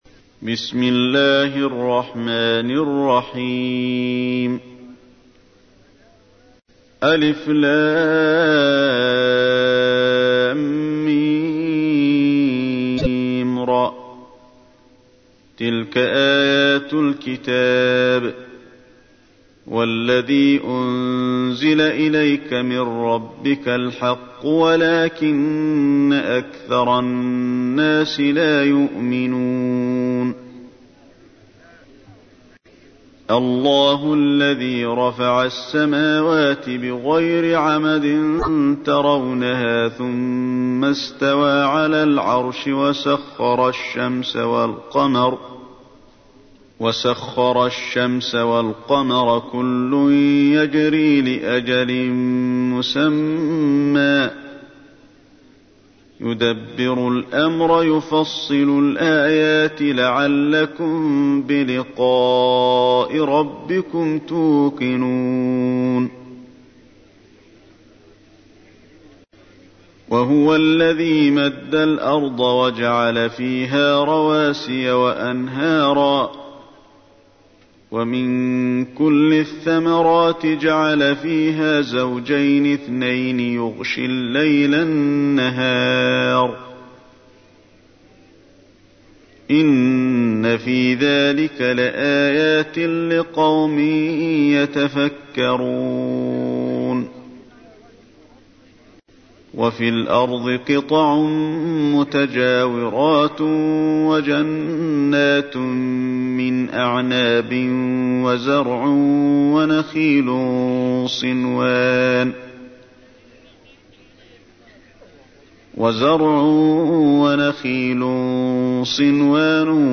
تحميل : 13. سورة الرعد / القارئ علي الحذيفي / القرآن الكريم / موقع يا حسين